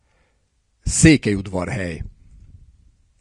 Odorheiu Secuiesc (Romanian pronunciation: [odorˌheju sekuˈjesk]; Hungarian: Székelyudvarhely, Hungarian pronunciation: [ˈseːkɛjudvɒrhɛj]